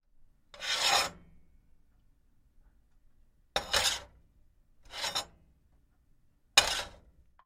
rooster and boiling pot
标签： stove chicken frying hor boiling rooster cooking pot country bubbling
声道立体声